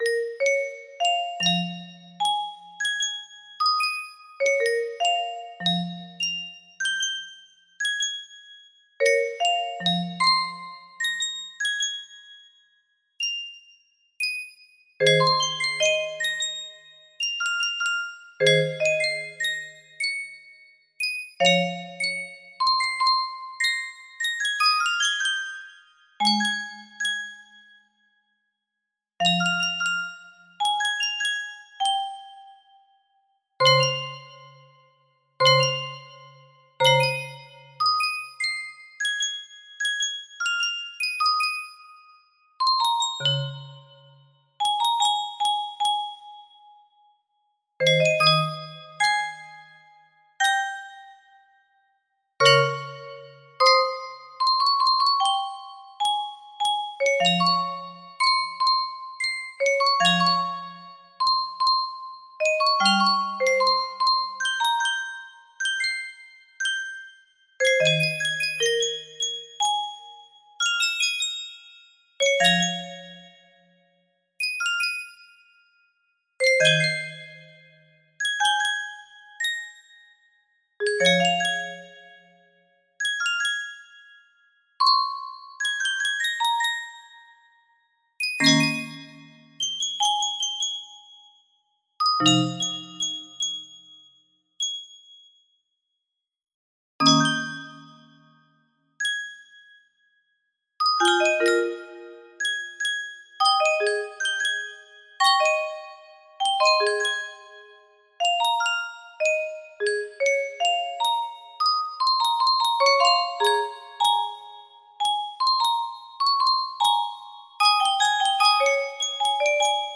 music box melody
Full range 60
no reds composed on site specifically for music box.